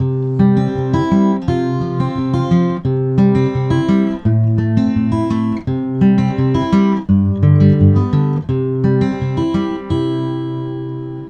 The last file is the same thing, but the dry, uncolvoluted sample.
Dry Sample
dry.wav